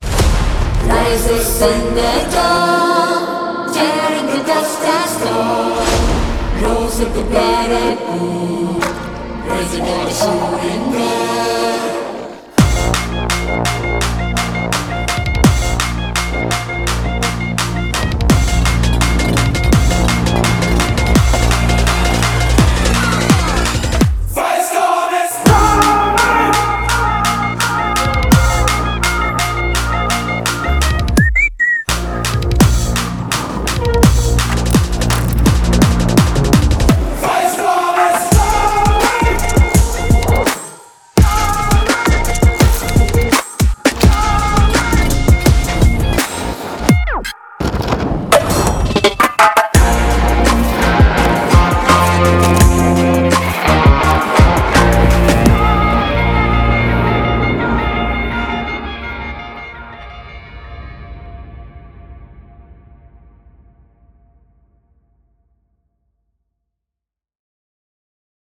without bullet sounds, sword sounds and disturbances